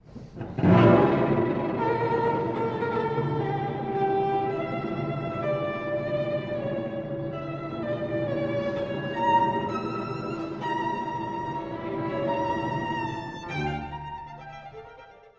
↑古い録音のため聴きづらいかもしれません！（以下同様）
Presto
～急速に～
稲妻のようなトレモロ（厳密には16分）。
1stバイオリンが激白するかのように歌います。
主題は、力強くも諦めを感じる、もの悲しい曲調。